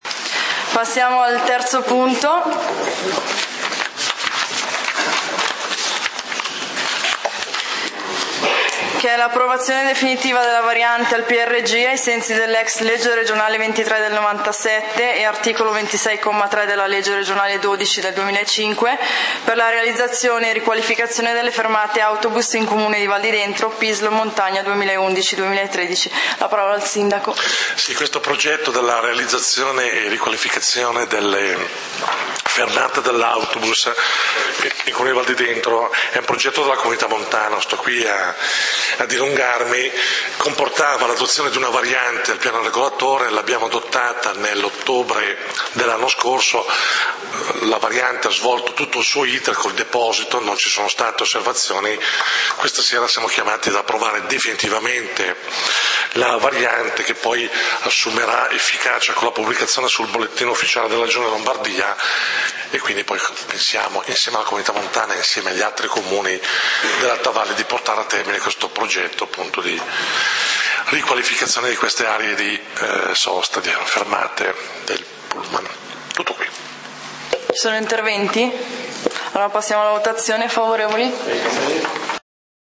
Consiglio comunale di Valdidentro del 30 Aprile 2014